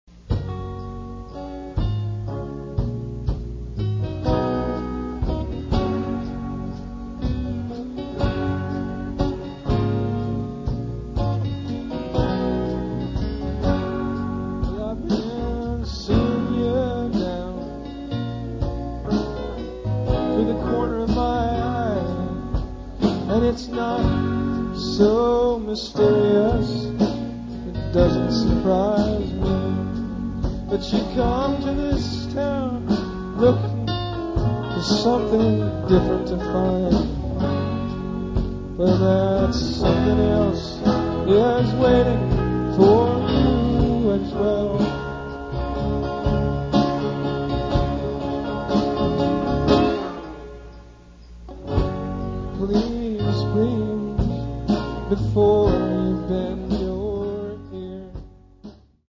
Sessions radios & lives inédits
Club Toast, Burlington - 1995